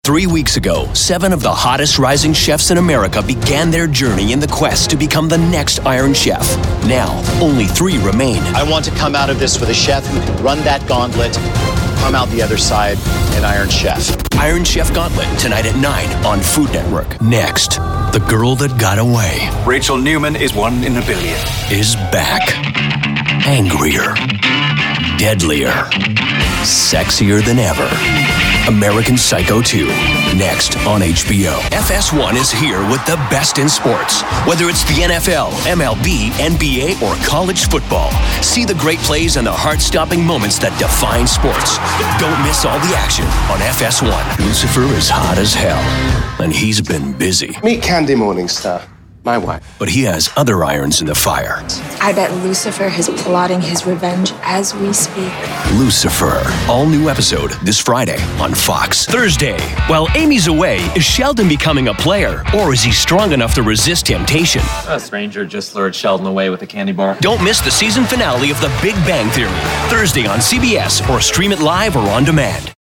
Young Adult
Middle Aged
18+ yrs full time Voice Actor with a Pro home studio and Source Connect.